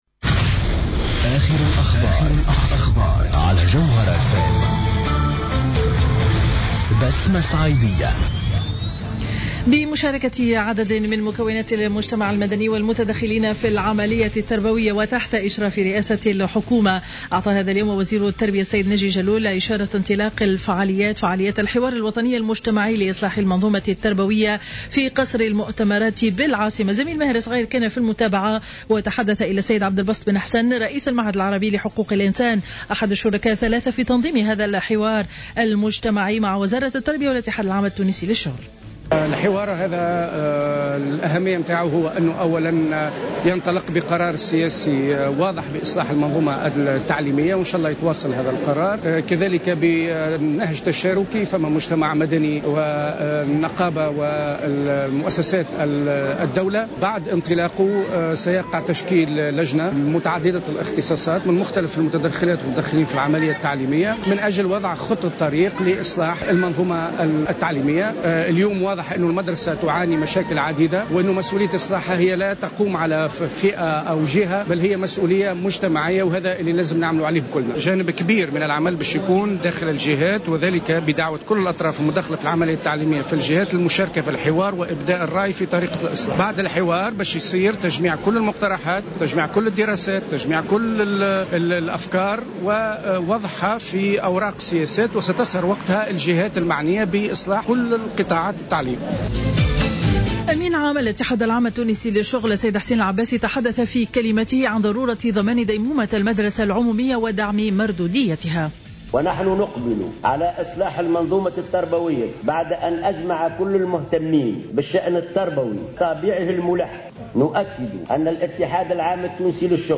نشرة أخبار منتصف النهار ليوم الخميس 23 أفريل 2015